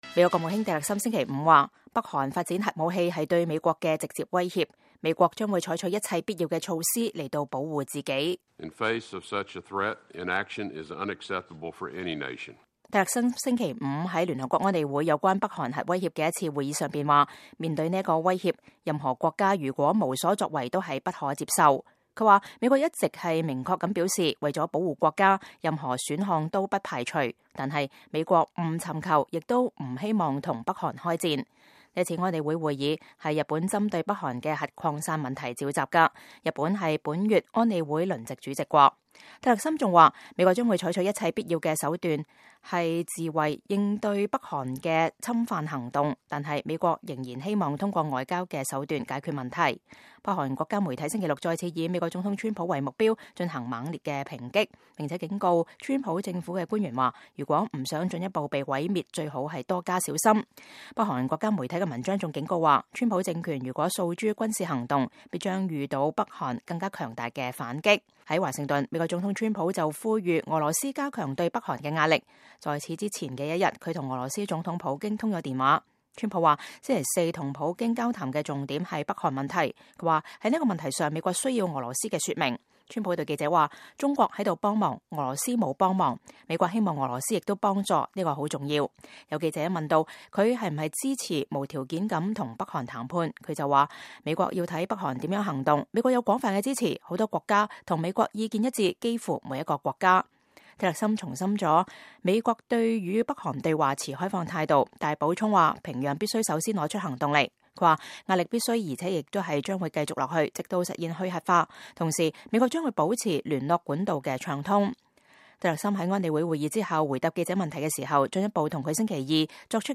美國國務卿蒂勒森在聯合國安理會圍繞北韓問題召開的高層會議上講話 (2017年12月15日)